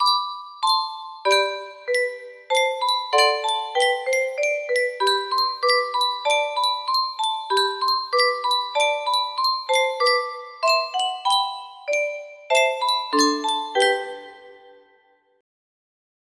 Clone of Yunsheng Spieluhr - Summ, summ, summ 4593 music box melody